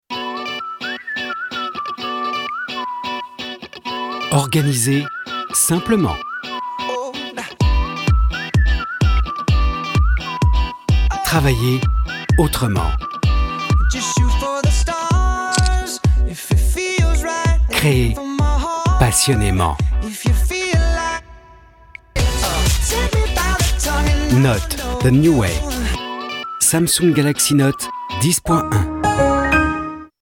France voix talent voice professionnel
Sprechprobe: Werbung (Muttersprache):